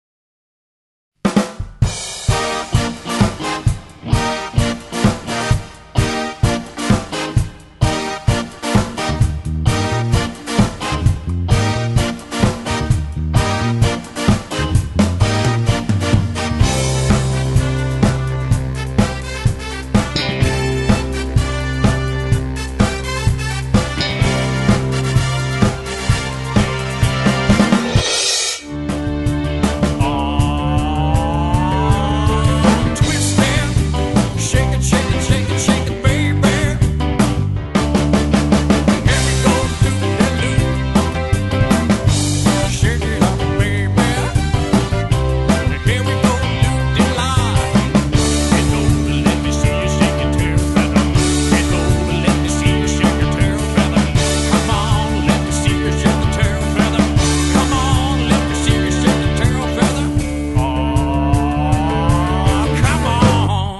cover bands
classic funky tunes